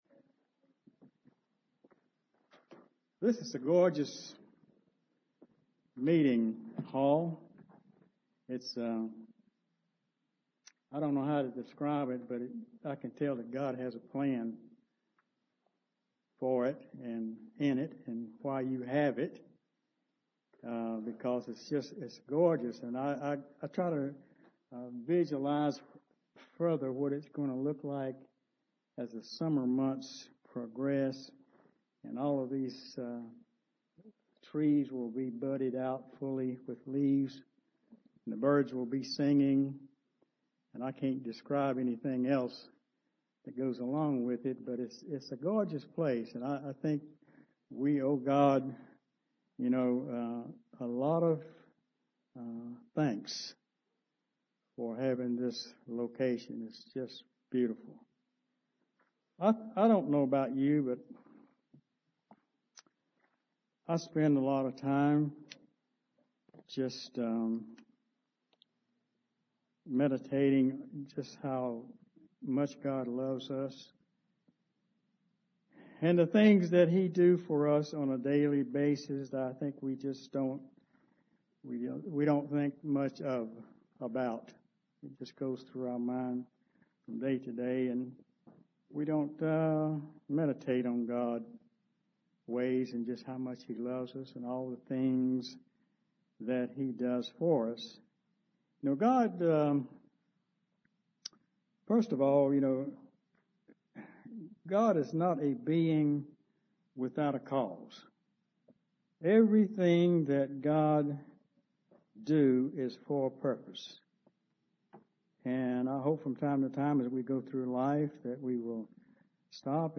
Sermons
Given in Charlotte, NC Columbia, SC Hickory, NC